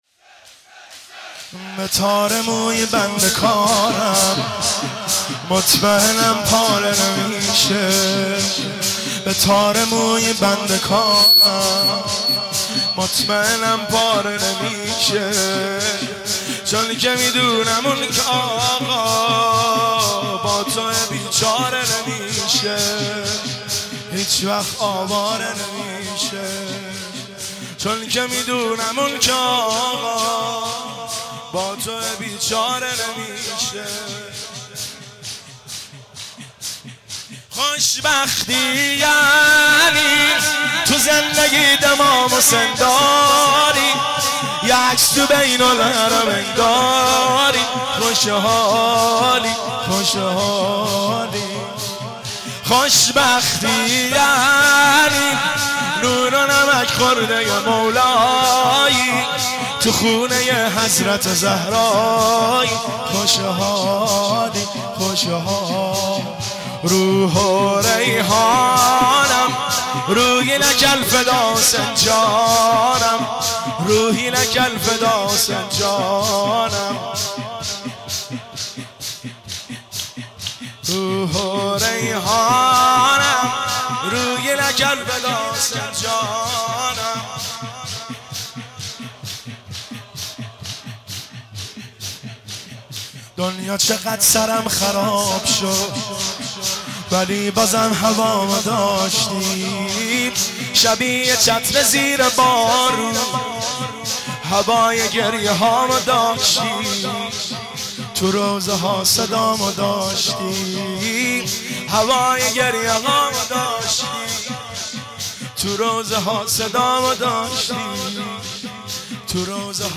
از همه شاهای دو عالم سری - شور